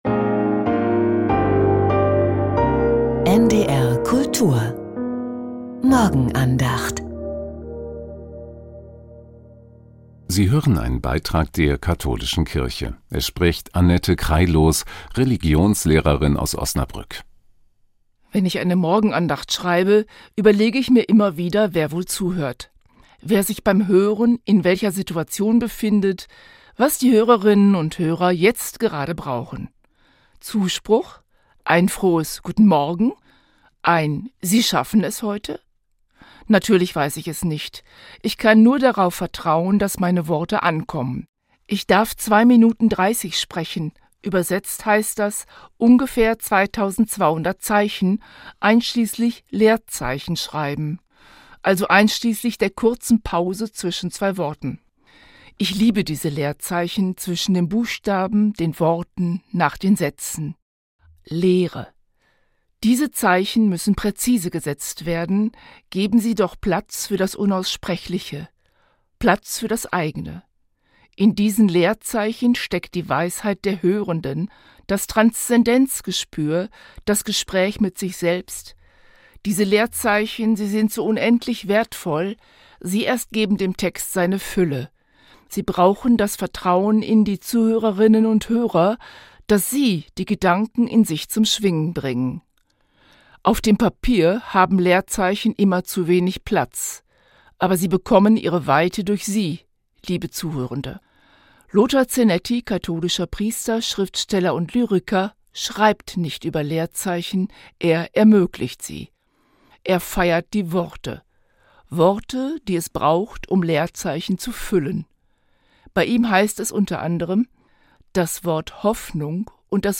Leerzeichen ~ Die Morgenandacht bei NDR Kultur Podcast